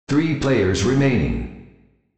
Announcer
ThreePlayersRemaining.wav